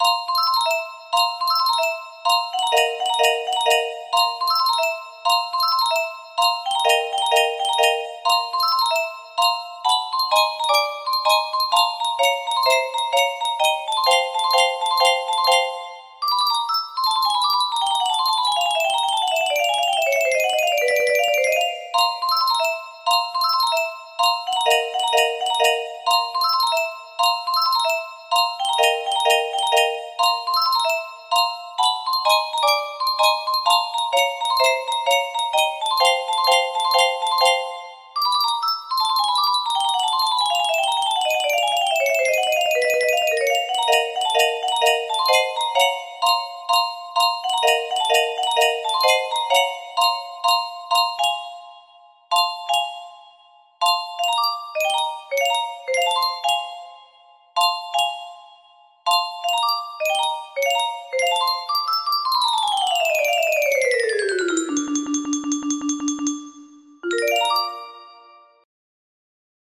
Aquarium _ Camille Saint-Säens music box melody
Aquarium adapted for 30 notes